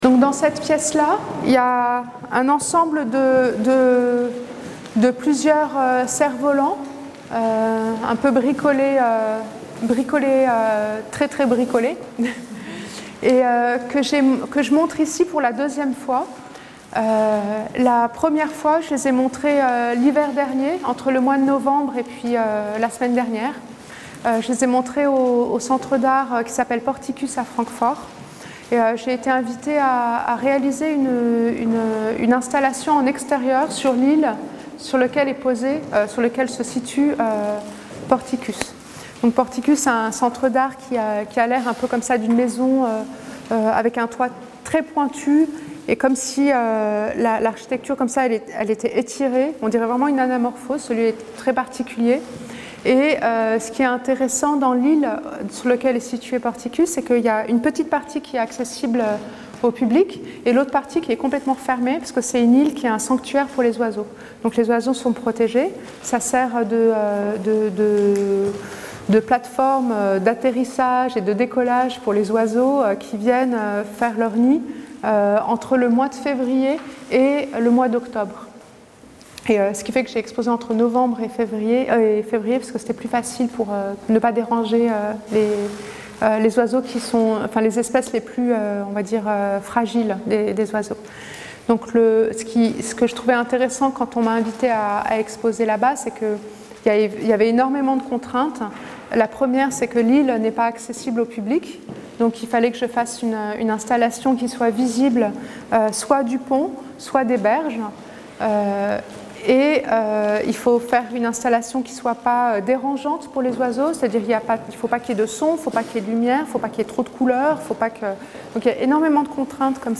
Le macLYON a proposé à Latifa Echakhch de parler de chacune des œuvres présentes dans l'exposition Laps, et a intégré ces séquences sonores à ses cartels.